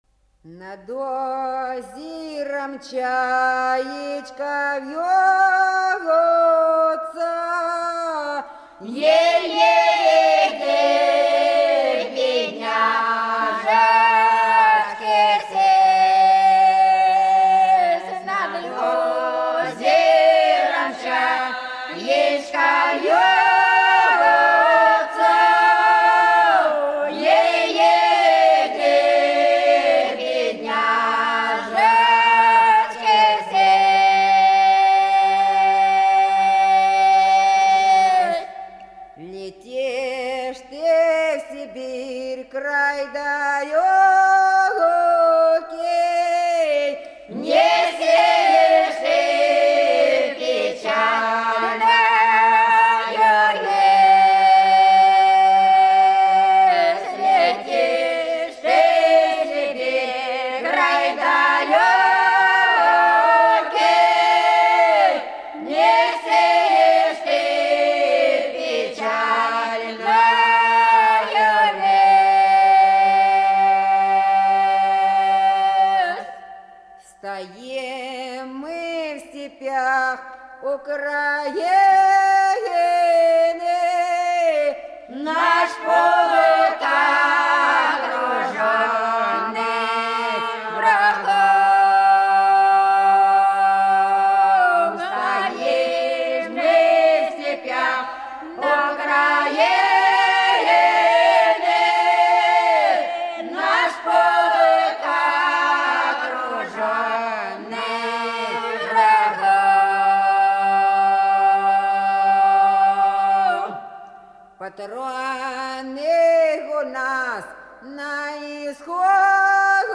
Голоса уходящего века (Курское село Илёк) Над озером чаечка вьётся (протяжная)